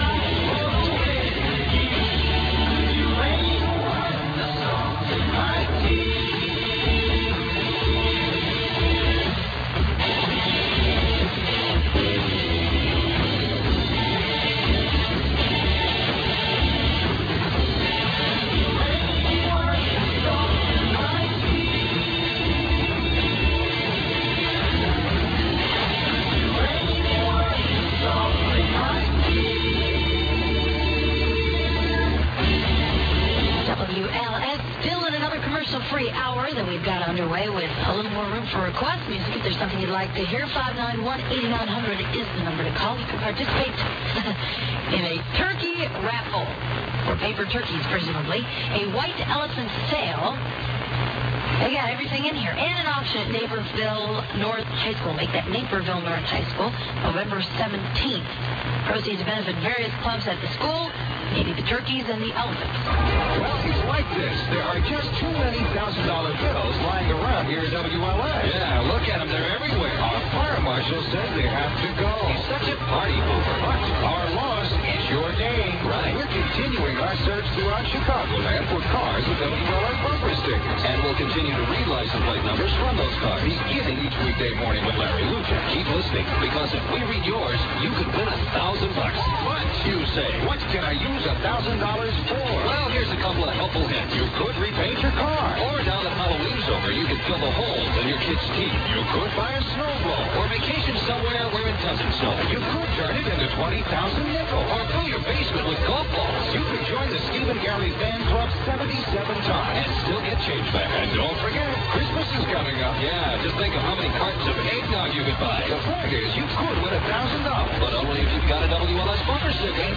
Early (1984) AM Stereo Tests, (Harris system)
In Stereo.
This was received with a modified AM Belar/Harris/C-QUAM receiver (decoder).